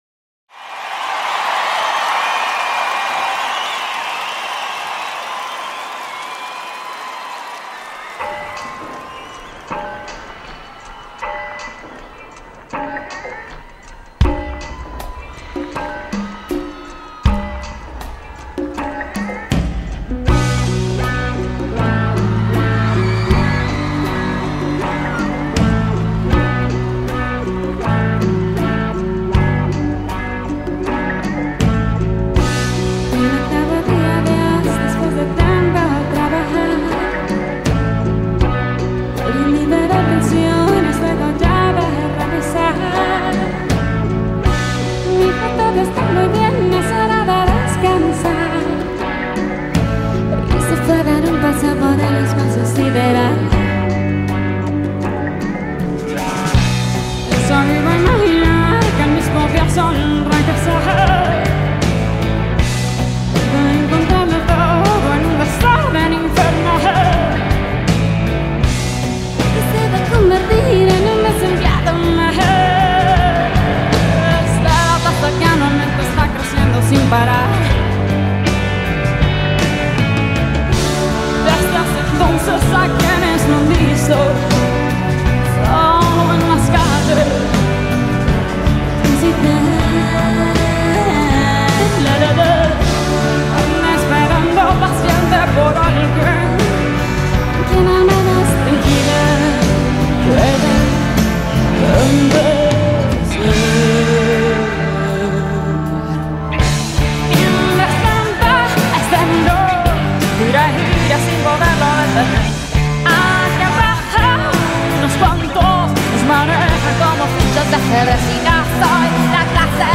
Latin Pop, Acoustic Rock, Live
Grand Ballroom, Manhattan Center Studios, New York